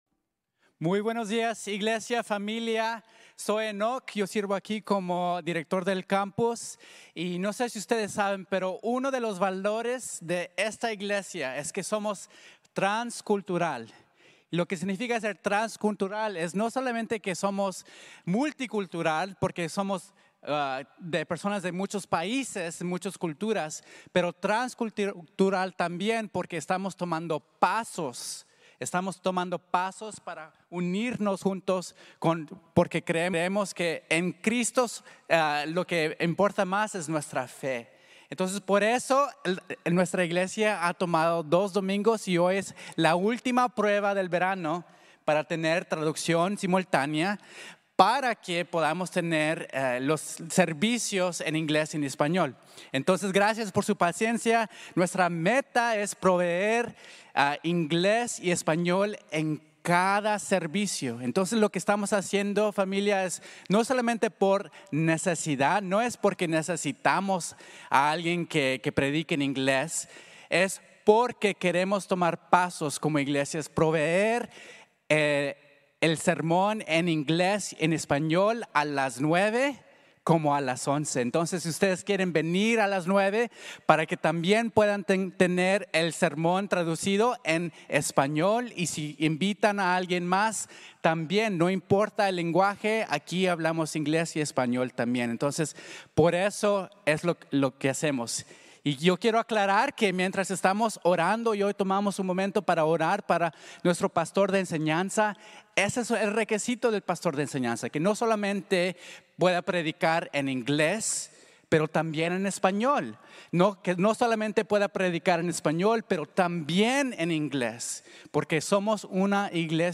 El abrumador amor de Dios | Sermon | Grace Bible Church